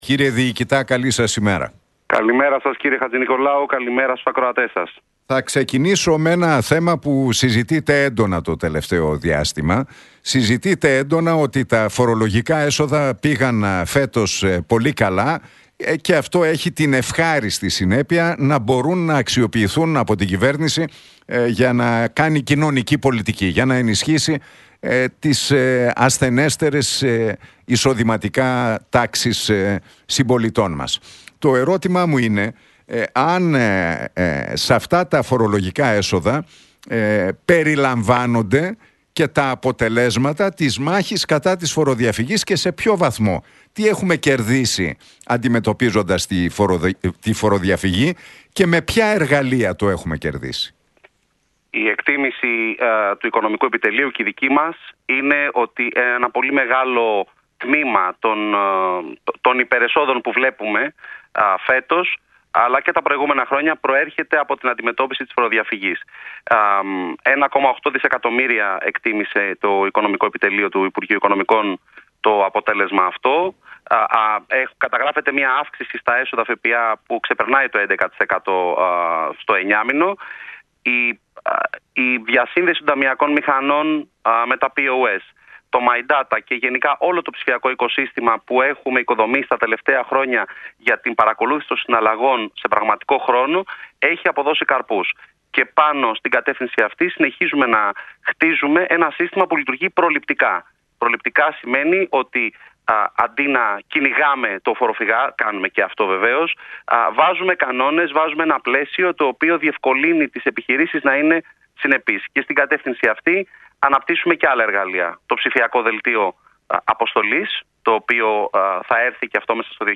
«Η εκτίμηση του οικονομικού επιτελείου και η δική μας είναι ότι ένα μεγάλο τμήμα των υπερεσόδων που βλέπουμε φέτος αλλά και τα προηγούμενα χρόνια προέρχεται από την αντιμετώπιση της φοροδιαφυγής» τόνισε ο Γιώργος Πιτσιλής μιλώντας στον Realfm 97,8 και τον Νίκο Χατζηνικολάου.